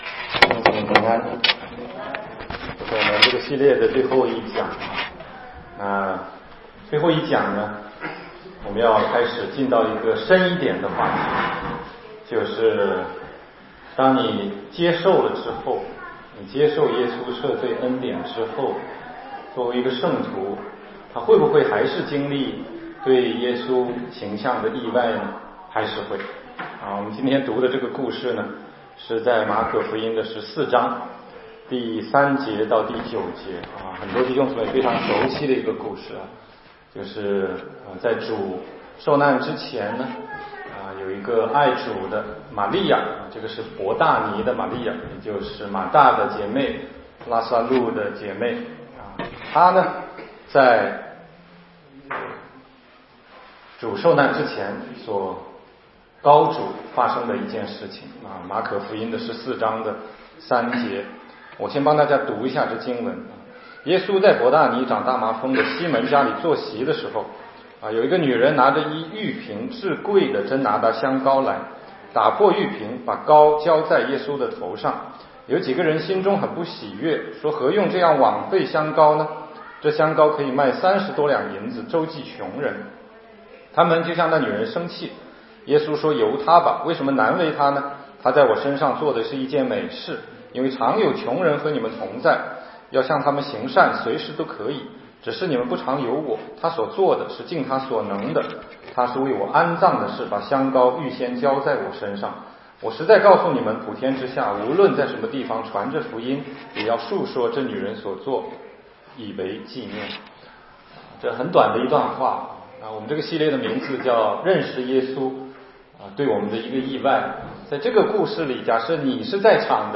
16街讲道录音 - 出人意料的耶稣—马利亚膏主
全中文查经